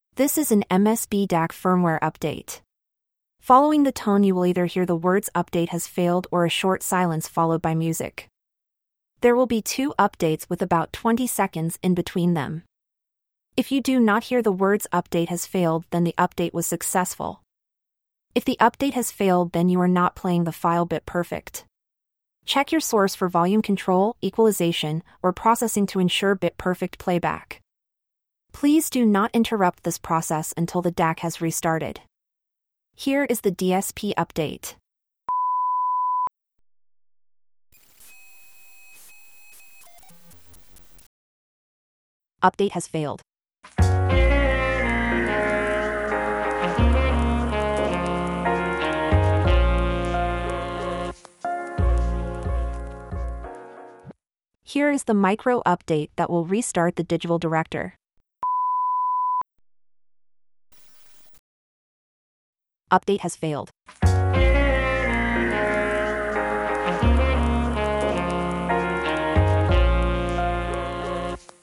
When you play the file you will hear instructions and two upgrade tones. Following each tone you will either hear silence for about 30 seconds (this varies) or you will hear the message ‘upgrade failed’.